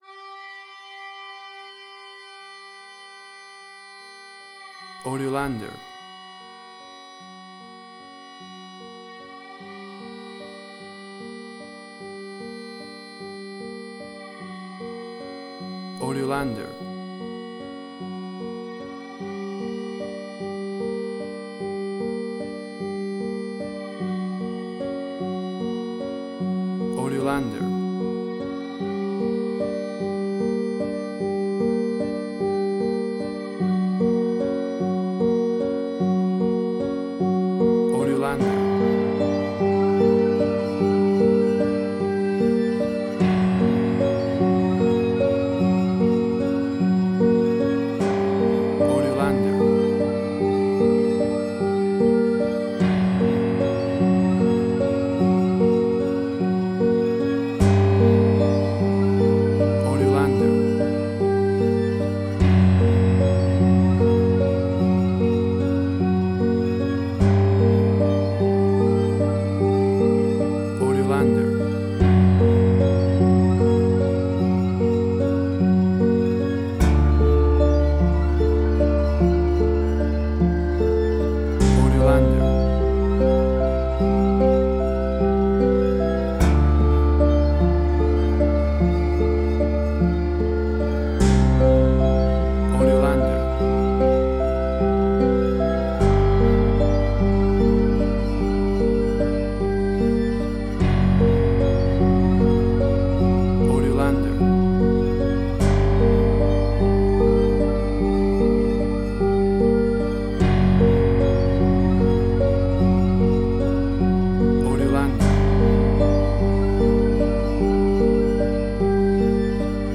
Suspense, Drama, Quirky, Emotional.
Tempo (BPM): 100